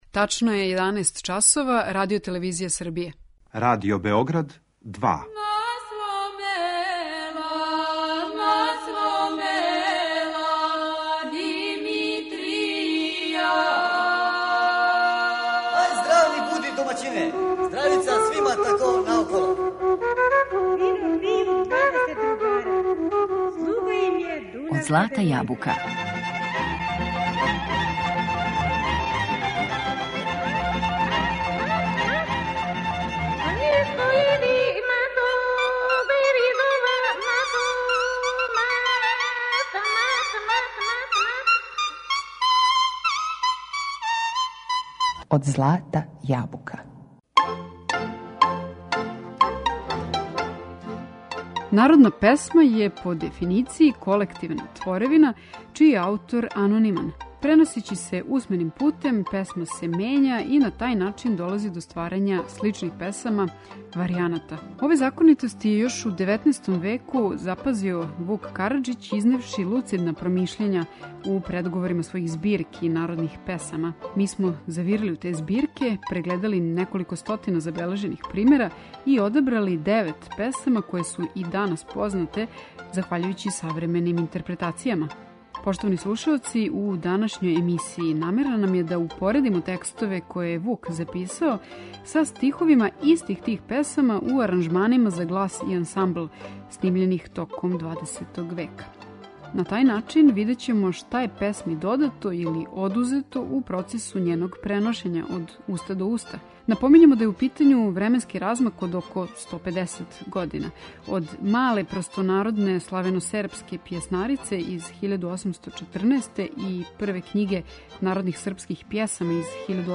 Упоредили смо текстове које је Вук записао са стиховима истих тих песама у аранжманима за глас и ансамбл, снимљених током 20. века.